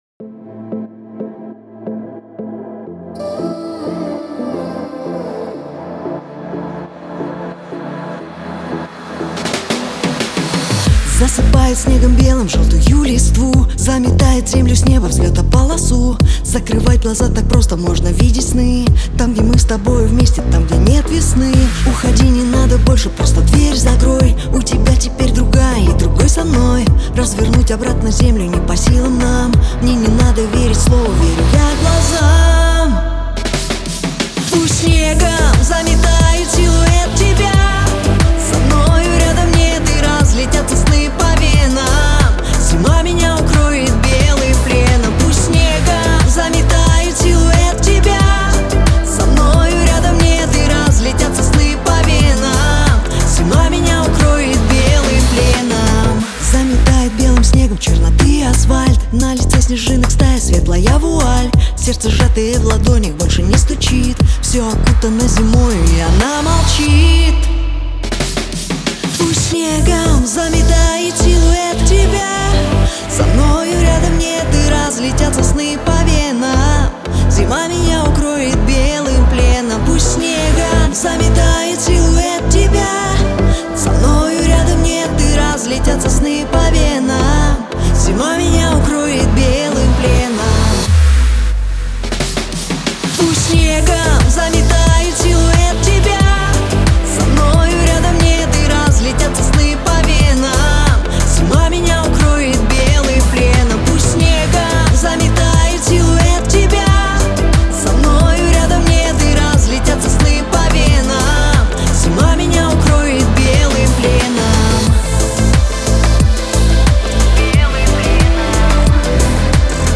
нежный вокал и атмосферные аранжировки